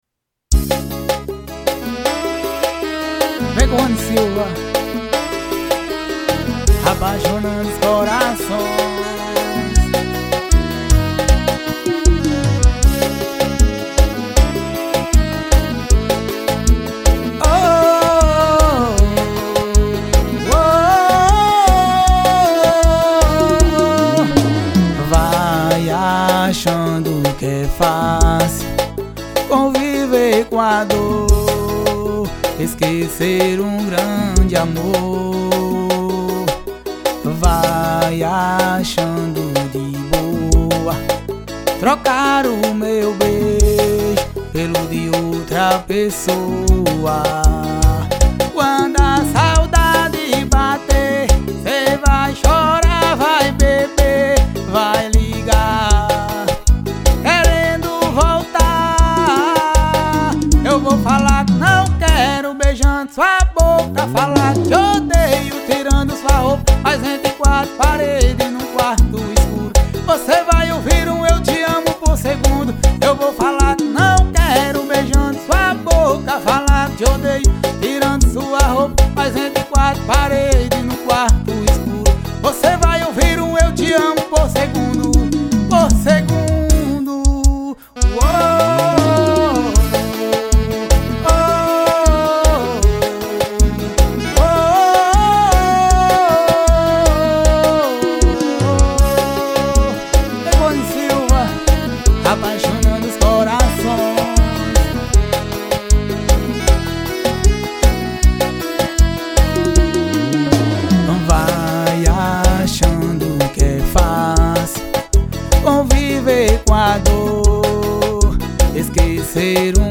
Cover Ao Vivo.